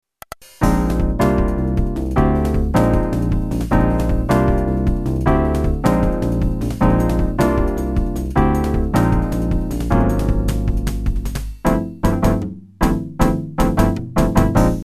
オンボード　FMシンセサイザ（ハード）[MP3ファイル]
FM音源のため音がこもり、音色も単純に聴こえる。いかにもFM音源らしい音。